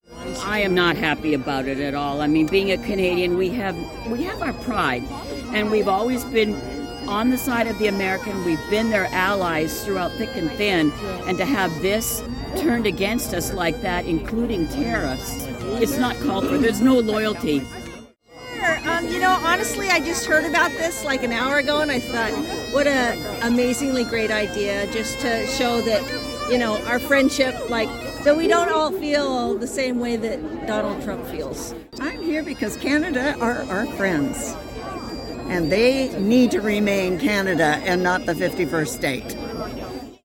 A welcoming event was planned by the Port Angeles Waterfront District, and about 150 people came down to show their support for Canada and the long and prosperous relationship between the cities of Port Angeles and Victoria.